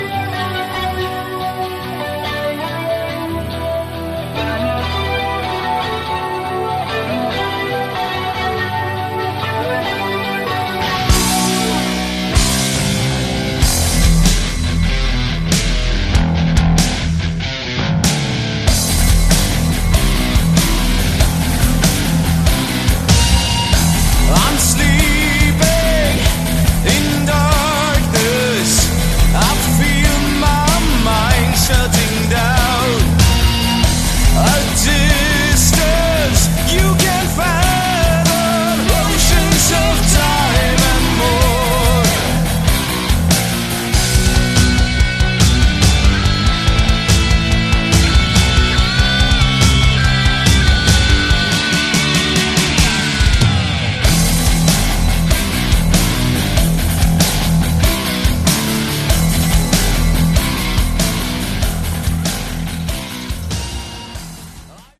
Category: AOR